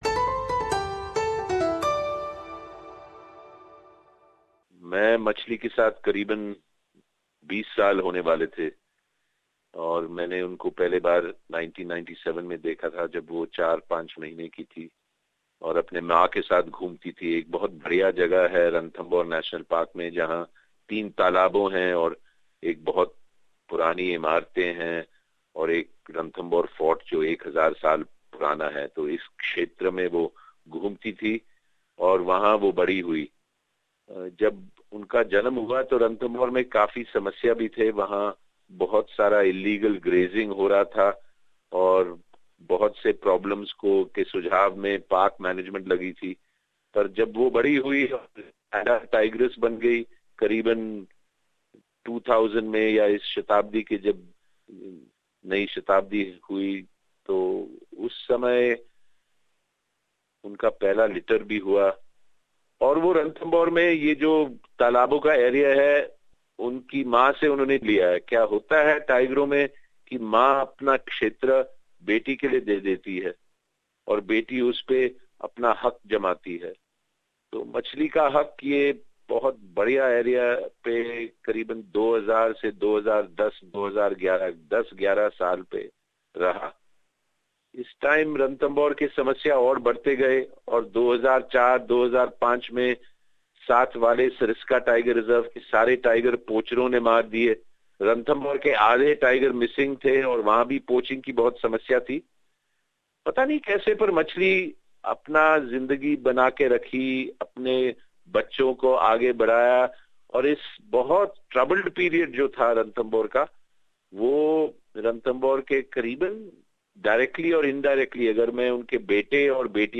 It is none other than the most photographed Tigress in the world Machali, the Indian Tigress who lived over 19 years of age and recently passed away. We spoke to well known Conservationist and Naturalist Valmik Thapar who knew Machali since she was a cub.